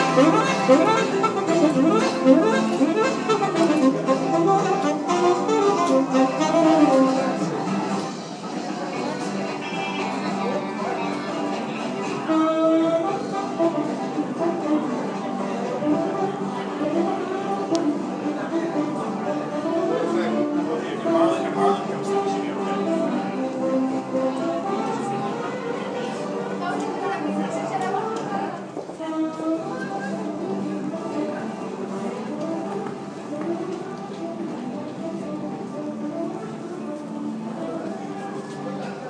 Barcelona subway